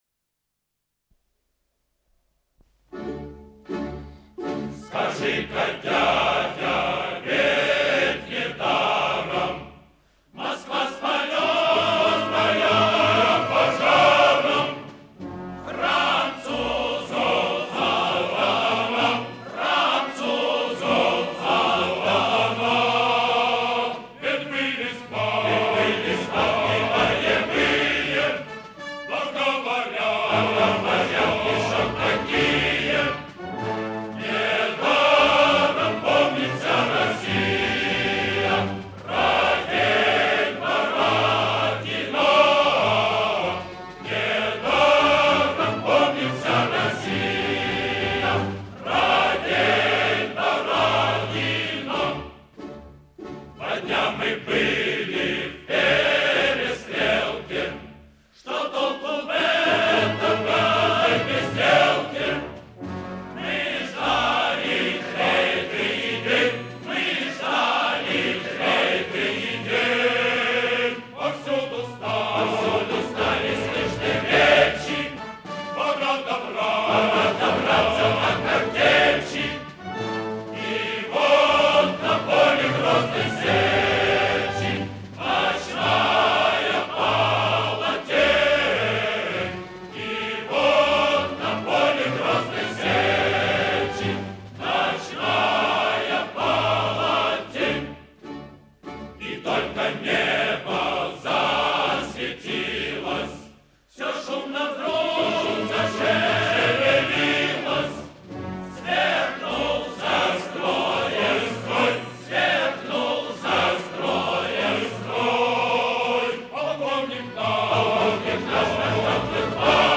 файл) 8,24 Мб Русская народная песня «Бородино» на слова М.Ю. Лермонтова. 1